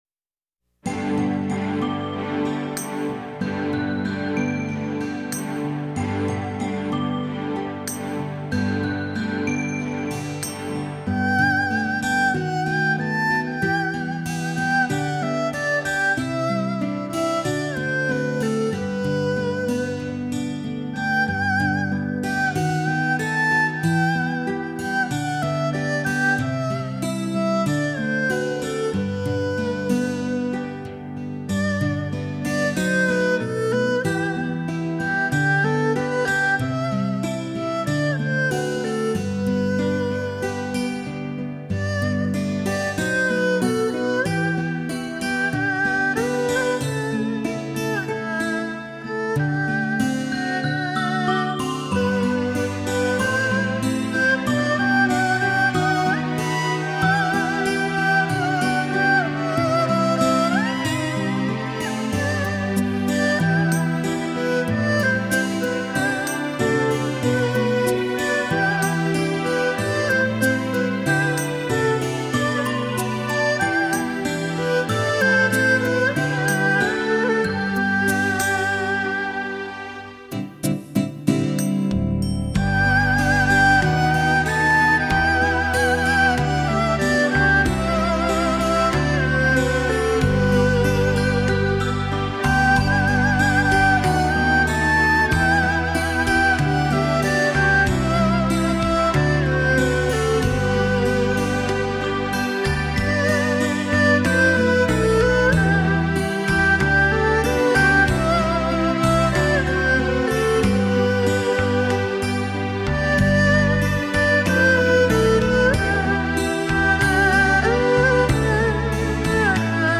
大提琴